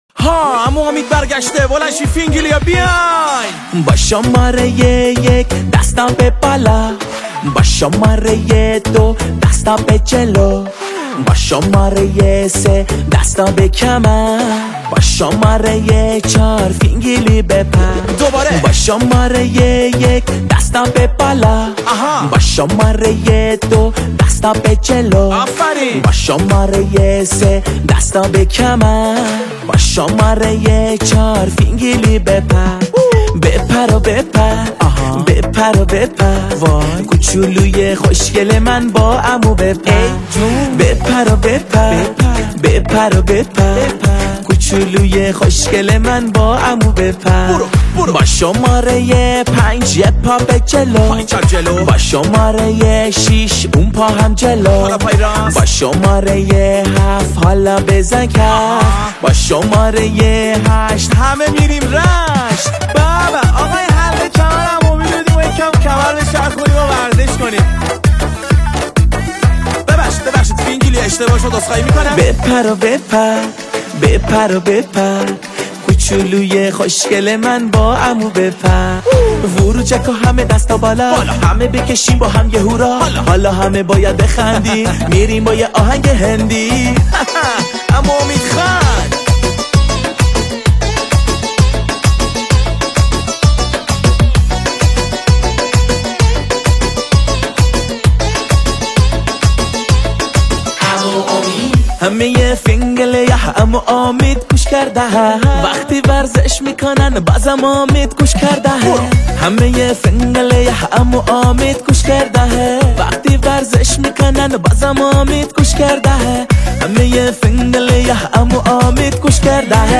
آهنگ ورزشی مناسب مهد و پیش دبستانی